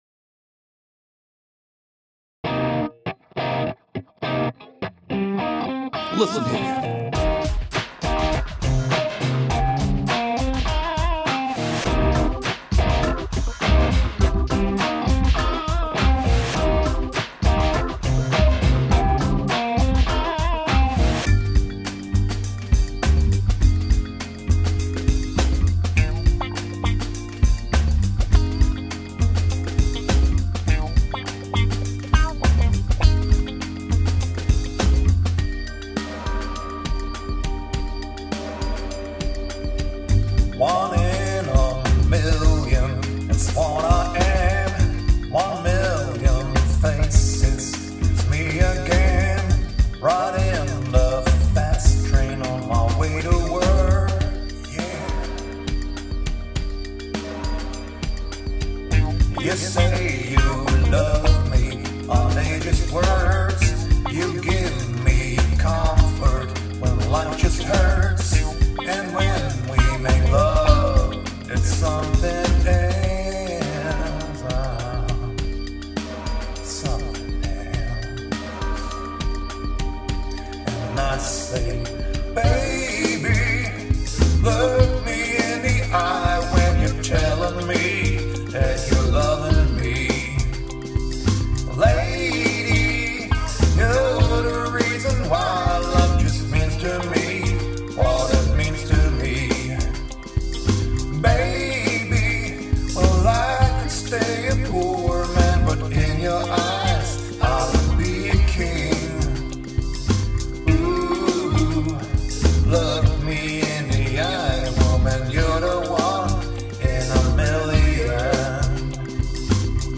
Demo
Compare with the original track recorded in 2013:
heartfelt soul/R&B song